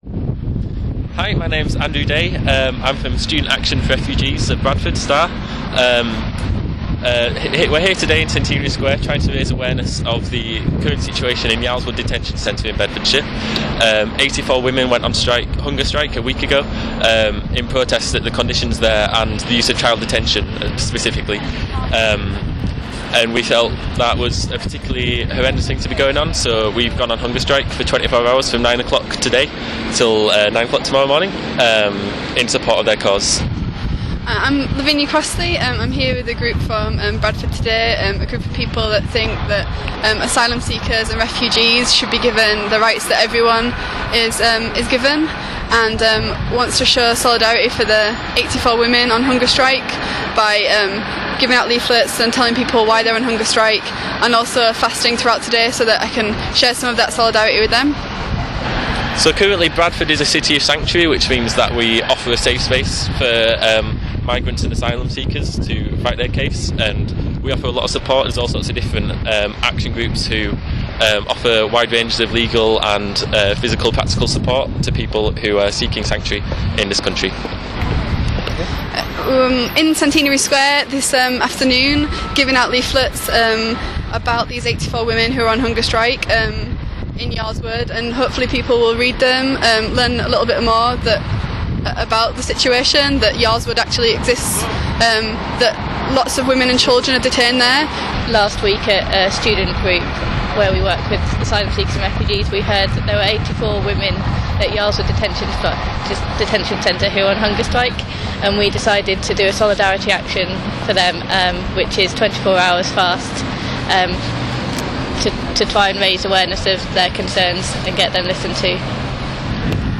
Sound Audio Interview with Hunger Strikers